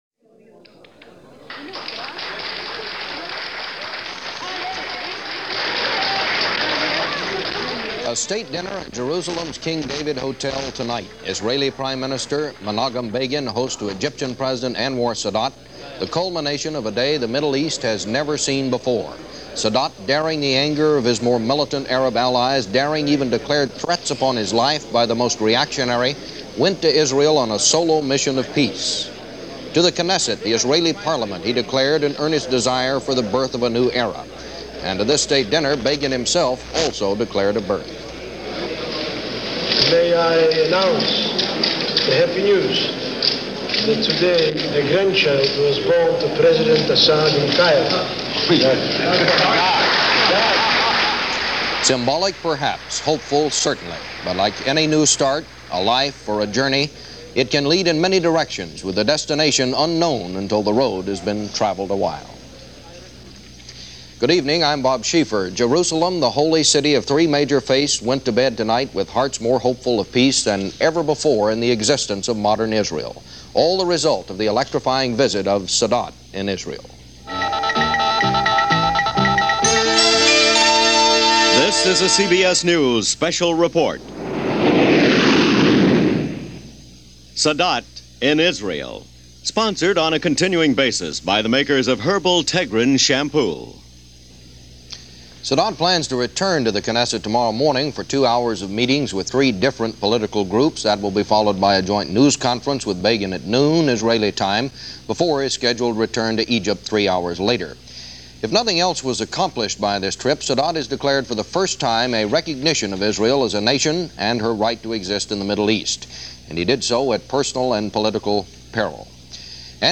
CBS Radio